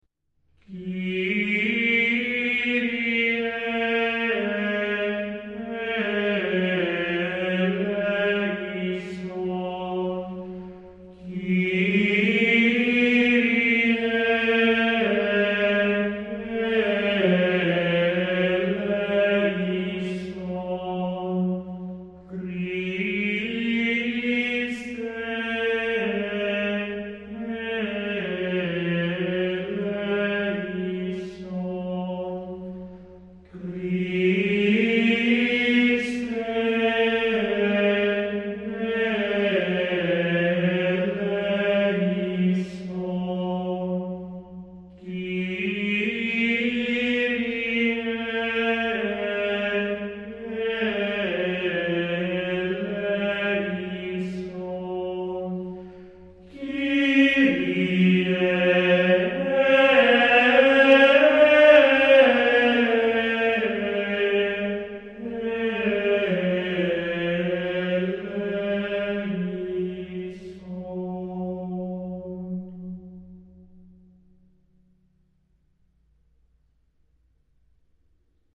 Fra i Kyrie più celebri, il XVIII, quello che si adotta nella messa pro defunctis, accoglie la struttura più semplice: il tema è lo stesso per tutte le ripetizioni (qui però la 'coda' è variata).
kyrie01.mp3